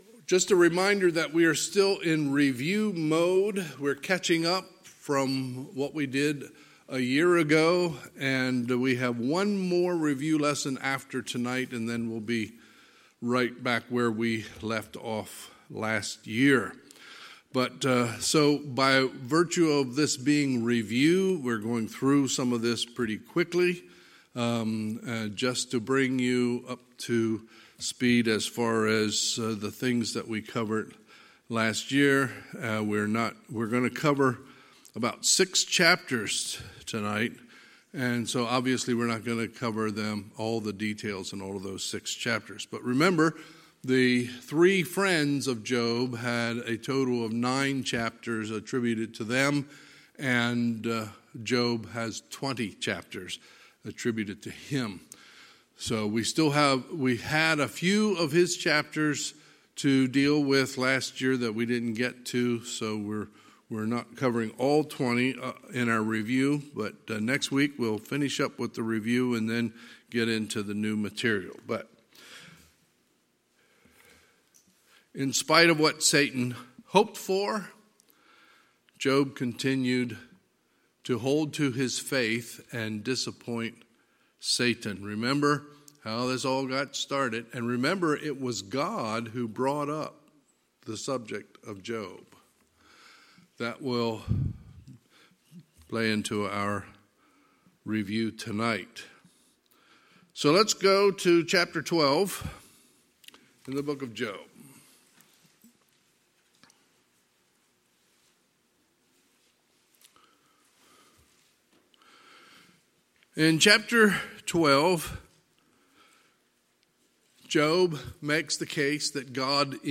*Note: due to a recording issue, this message is incomplete